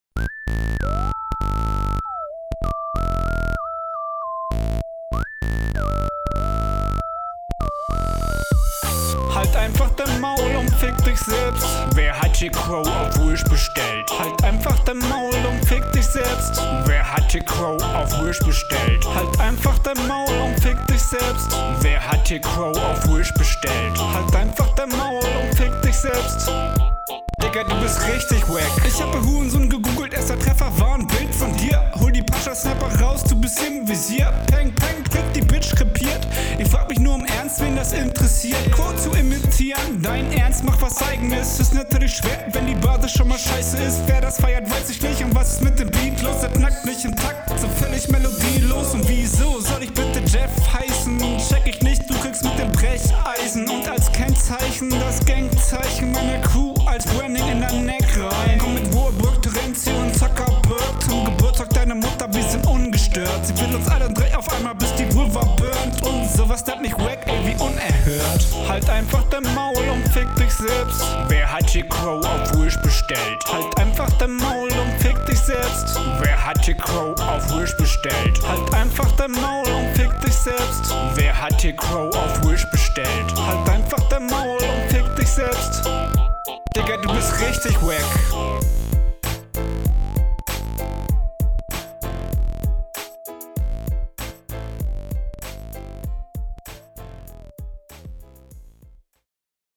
den autotune einsatz find ich sehr funny. du flowst cool aber bei dir ist die …